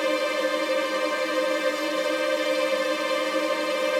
GS_TremString-Cmin9.wav